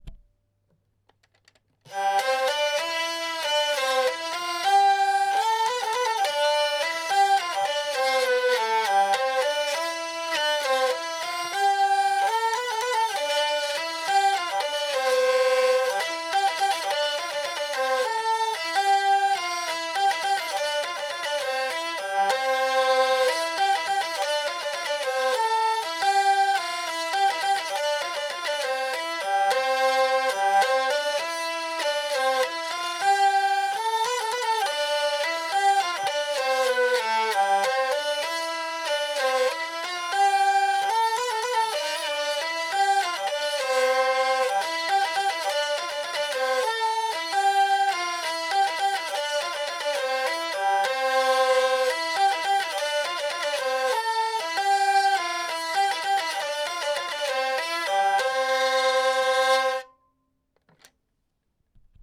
Lieu : Toulouse
Genre : morceau instrumental
Instrument de musique : vielle à roue
Danse : valse